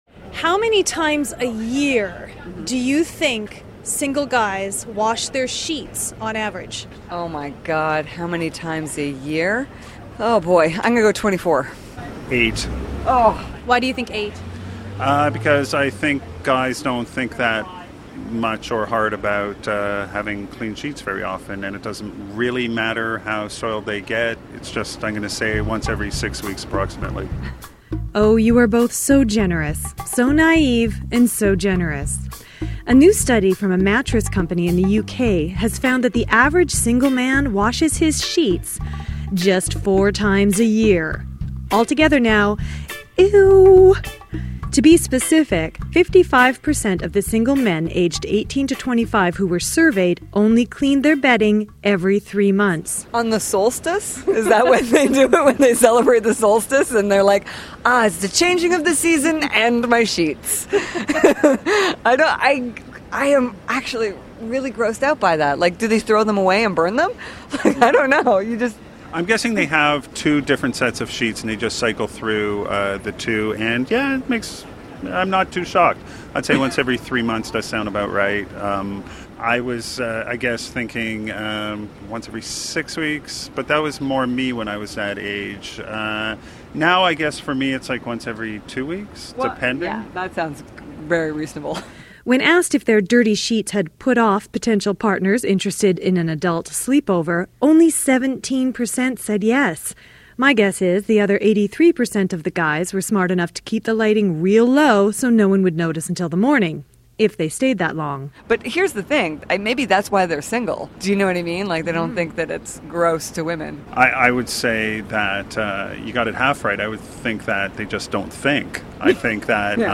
Click below to listen to the story that aired on CBC Radio 1 about how often guys wash their bedding.